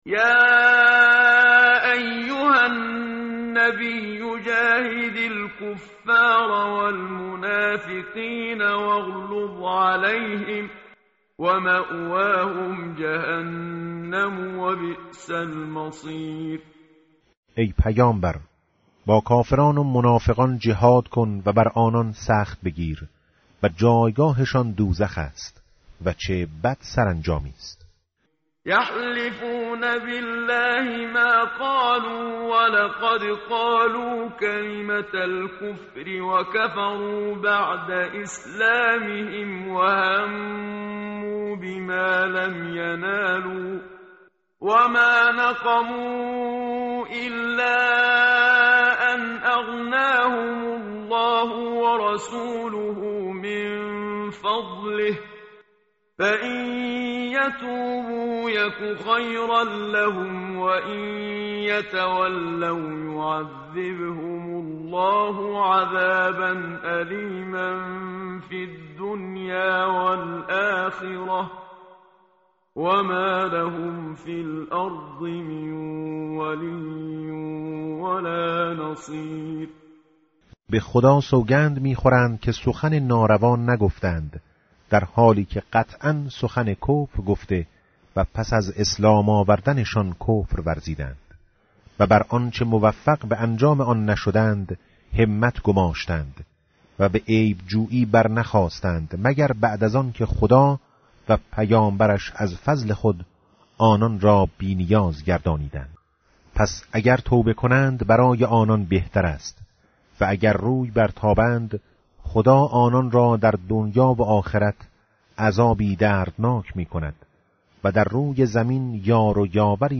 متن قرآن همراه باتلاوت قرآن و ترجمه
tartil_menshavi va tarjome_Page_199.mp3